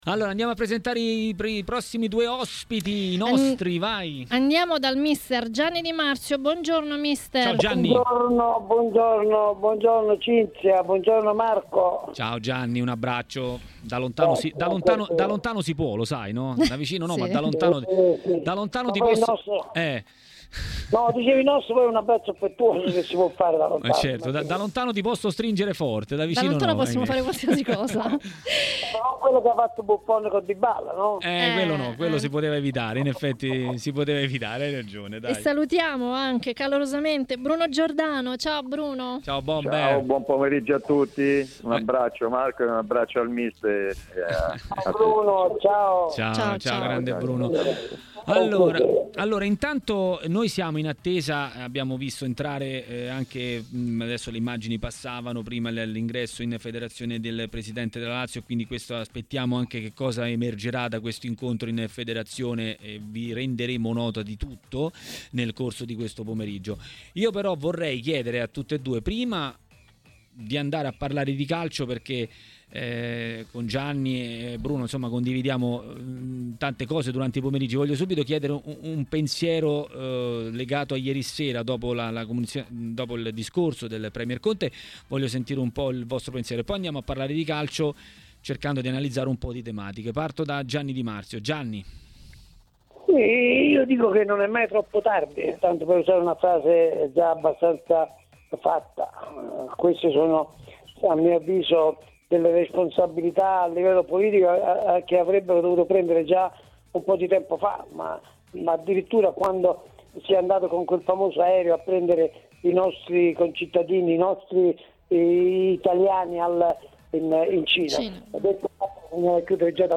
A dire la sua sui temi più caldi del calcio italiano e non solo è stato, in diretta a TMW Radio, durante Maracanà, l'ex calciatore e tecnico Bruno Giordano.